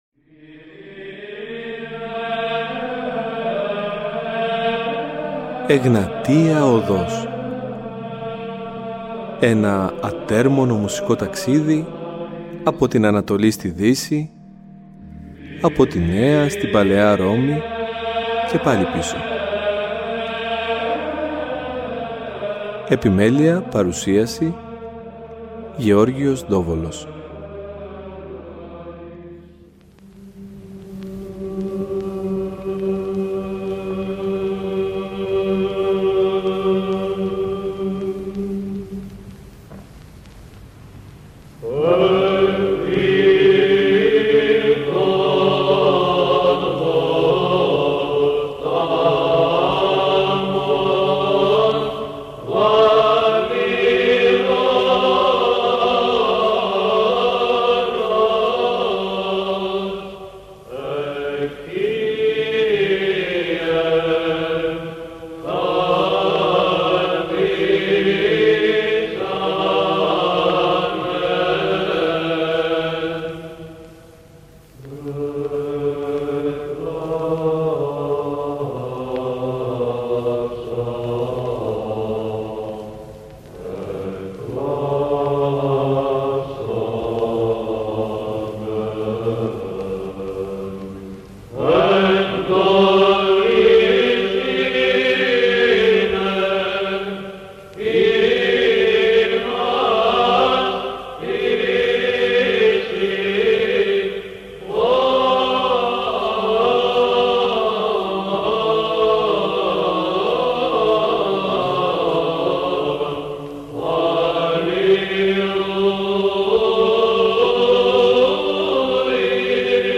Στην εκπομπή αυτής της Κυριακής ακούμε σπάνιες εκτελέσεις από καταξιωμένους ερμηνευτές της ψαλτικής τέχνης να αποδίδουν τον συγκεκριμένο ύμνο σε διάφορες μελοποιητικές προσεγγίσεις .